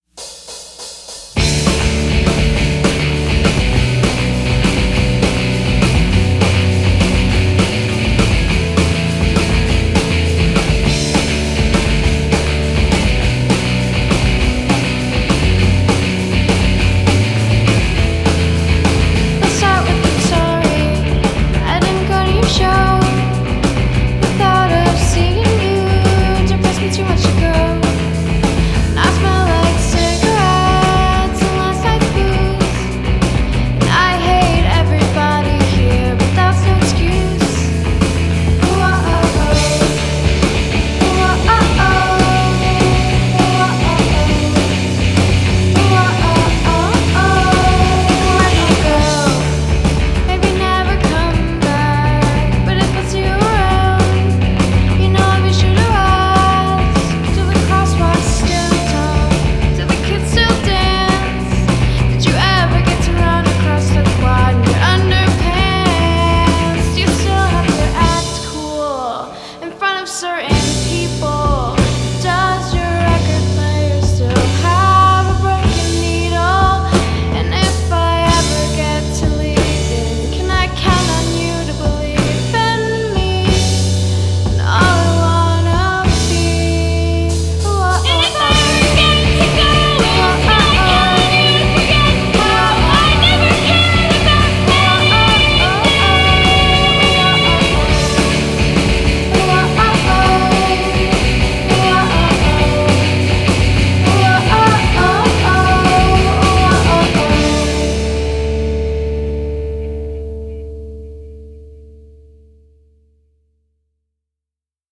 gioioso pop punk suonato con una sensibilità twee
chitarra e voce
batteria